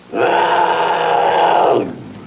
Leopard Roar Sound Effect
Download a high-quality leopard roar sound effect.
leopard-roar.mp3